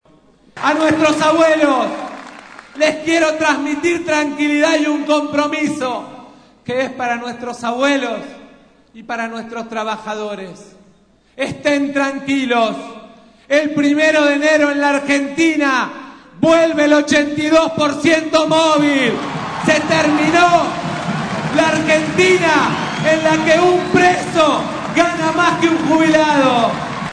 Mauricio Macri, líder de Propuesta Republicana (Pro), eligió como cierre los canales de televisión, donde expuso los motivos que lo llevaron a ser candidato presidencial y destacó la formación de su equipo de trabajo para cambiar la situación del país.
Palabras de Mauricio Macri